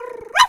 dog_2_small_bark_06.wav